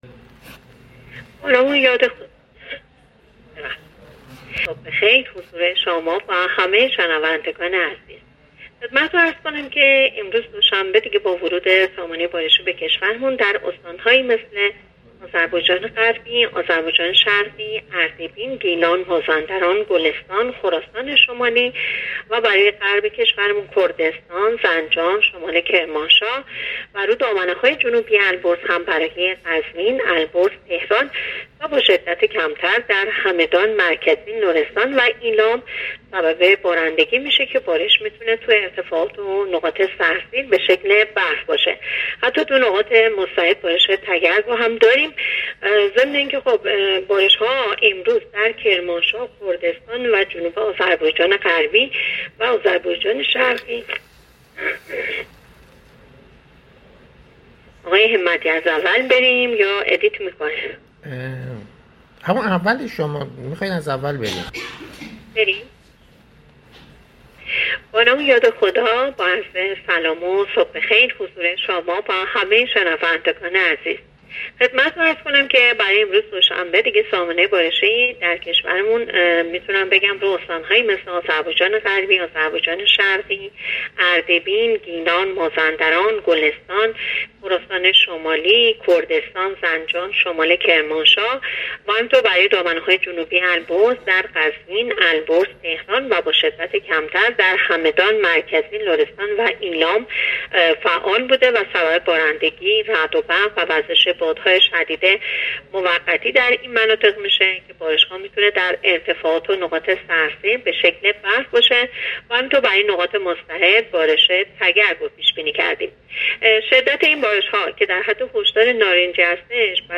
گزارش رادیو اینترنتی پایگاه‌ خبری از آخرین وضعیت آب‌وهوای ۲۶ آبان؛